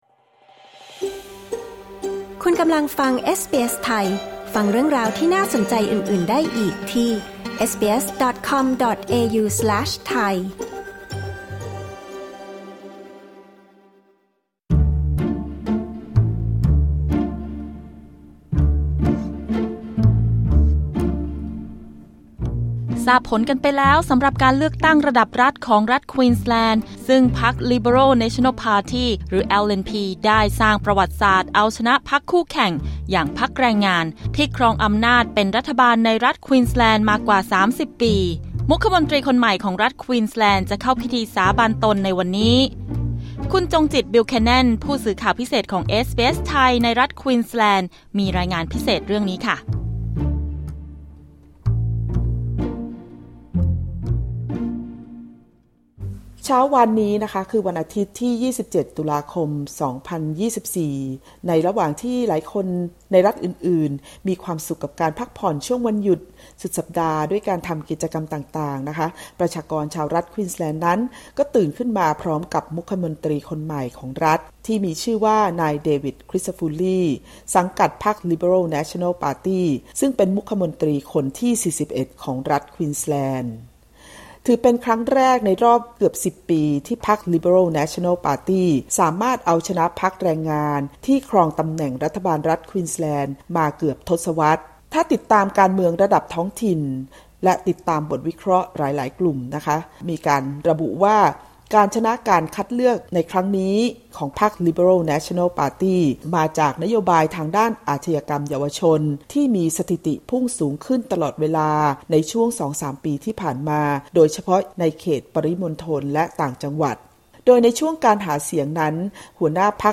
เอสบีเอสไทยรายงานผลการเลือกตั้งรัฐควีนส์แลนด์ที่พรรคร่วมหรือพรรคลิเบอรัล /เนชันแนล (LNP) เอาชนะพรรคแรงงานที่เป็นรัฐบาลในรัฐควีนส์แลนด์มายาวนานกว่า 3 ทศวรรษ พร้อมฟังความคิดเห็นคนไทยที่เป็นผู้มีสิทธิเลือกตั้งในครั้งนี้